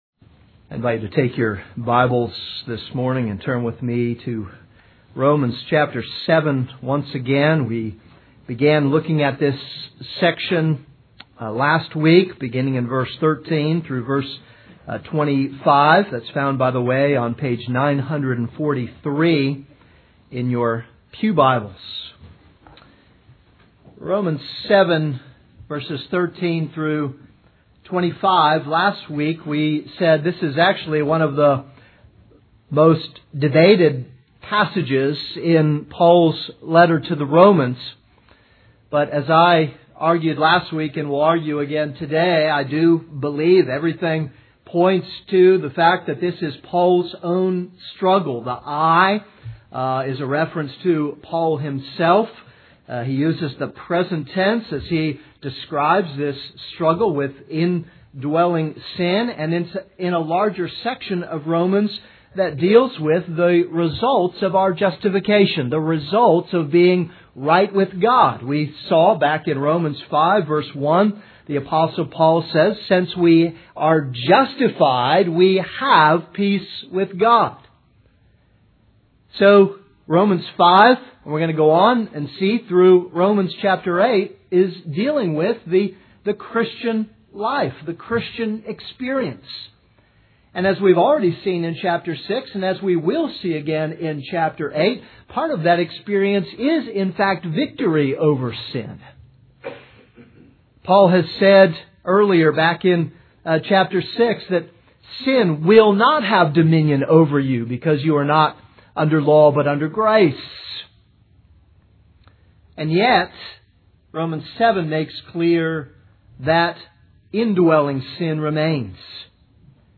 This is a sermon on Romans 7:13-25.